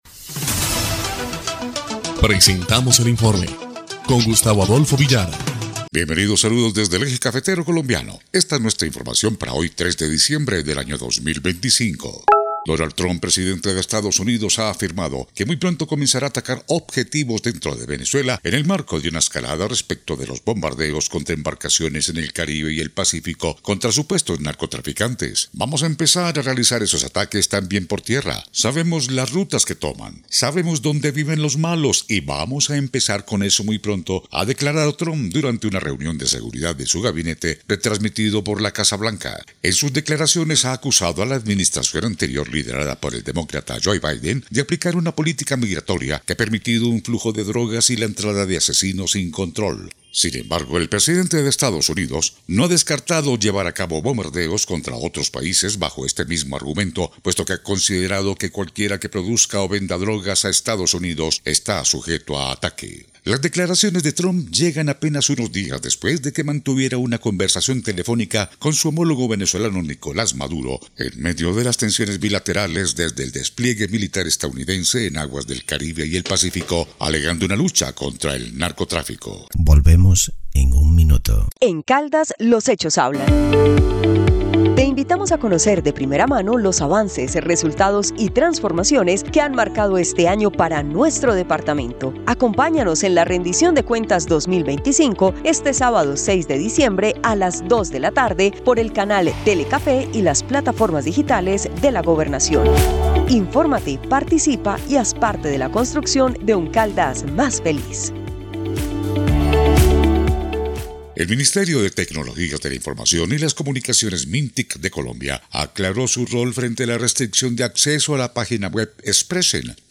EL INFORME 2° Clip de Noticias del 3 de diciembre de 2025